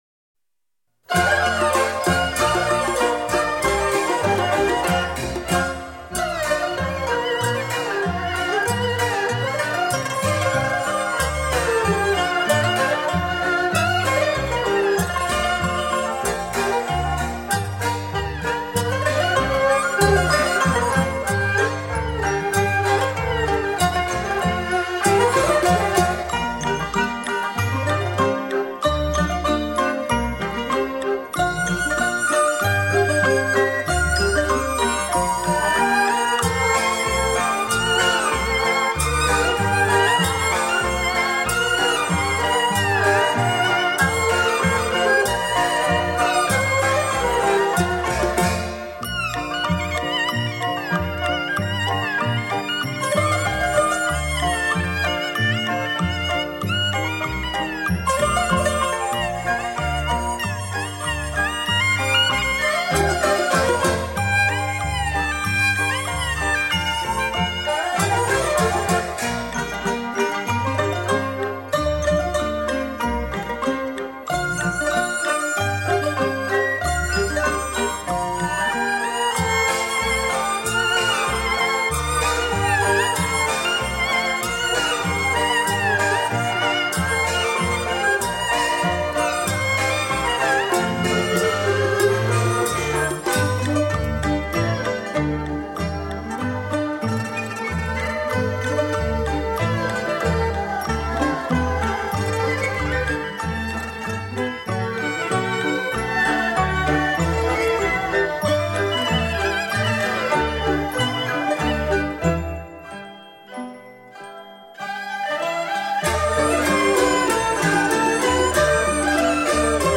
合奏) / 浙江民间曲调